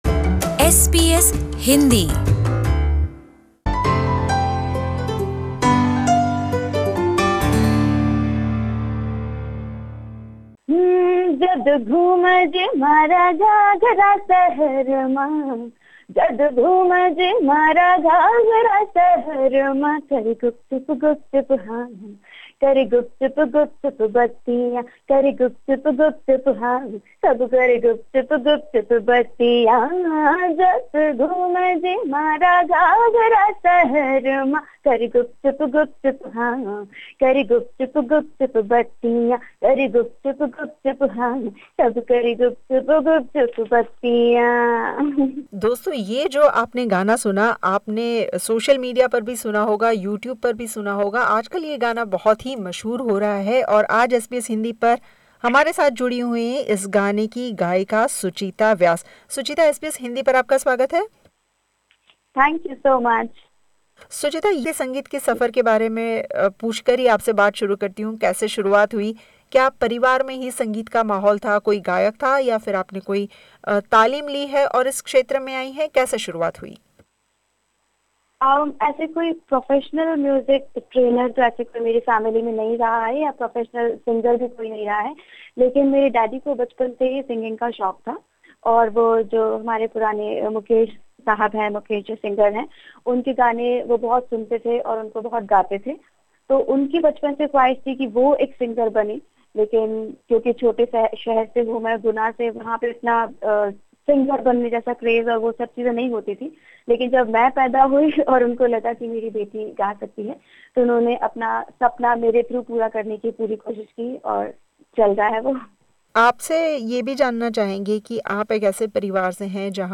बातचीत